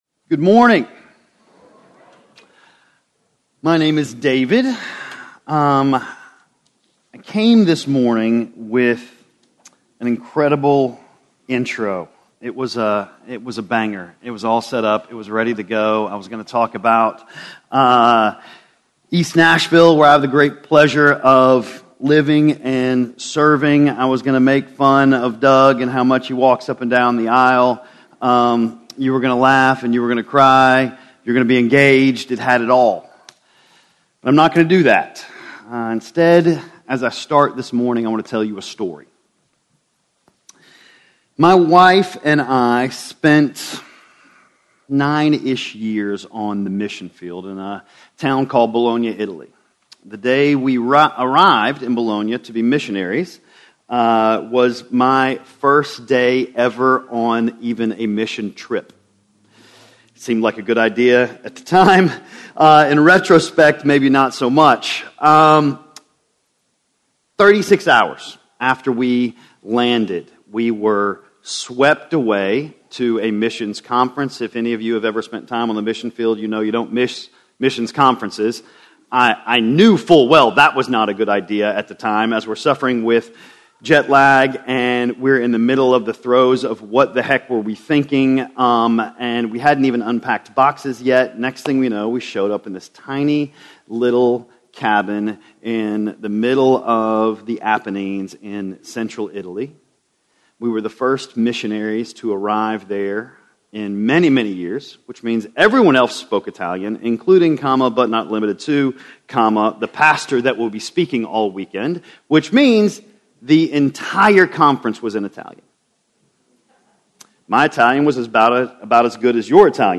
The Story of Restoration - Sermon - Woodbine